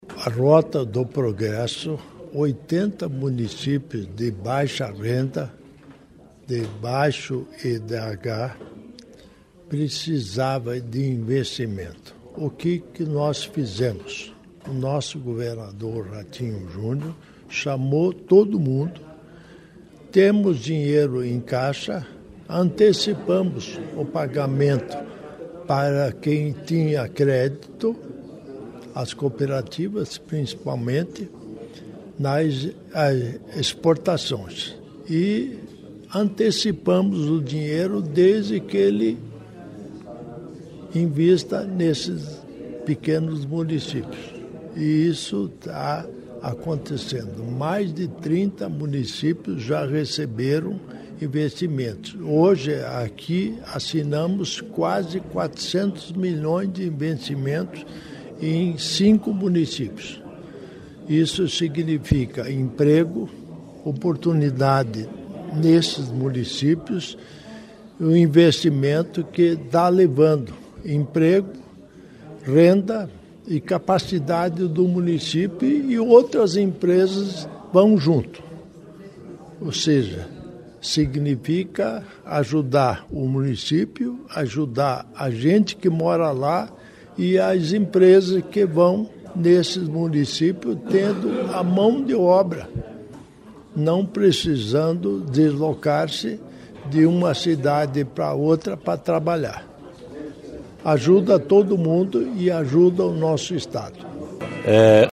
Sonora do governador em exercício Darci Piana sobre a atração de investimentos pelo Rota do Progresso para cinco cidades